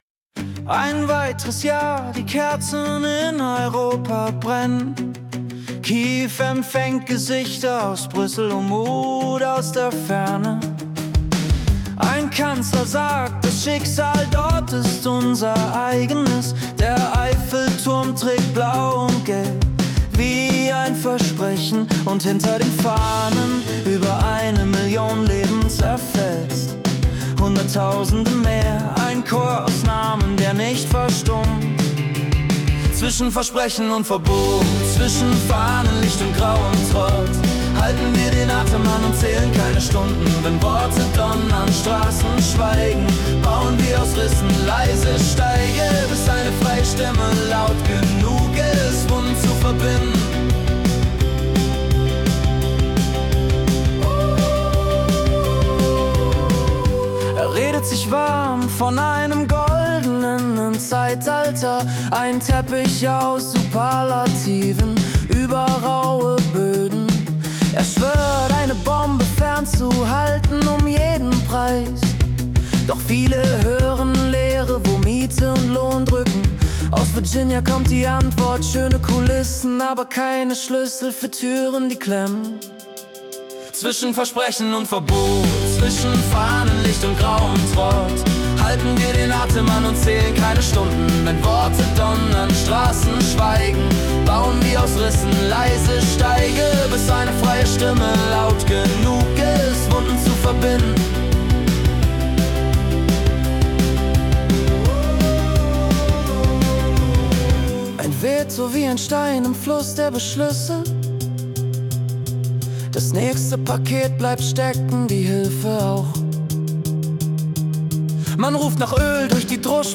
Die Nachrichten vom 26. Februar 2026 als Singer-Songwriter-Song interpretiert.